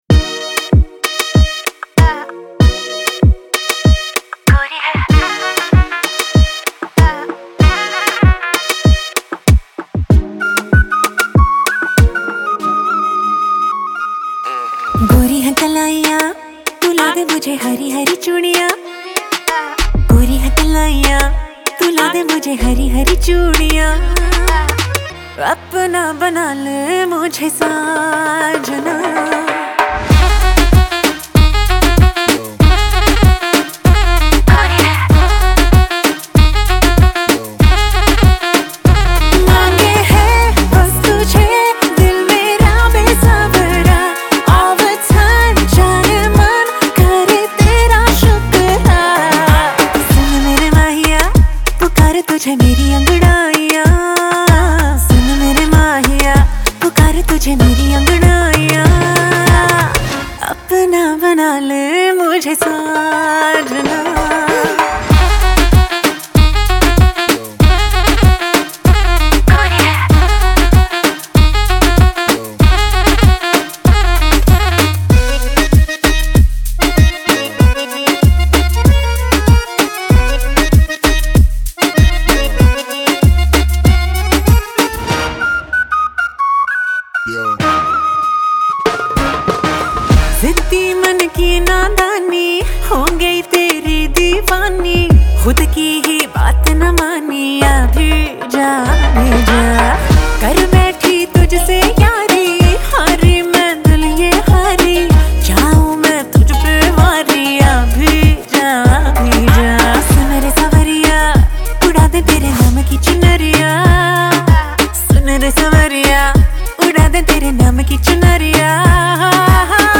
More Songs From Indipop Mp3 Songs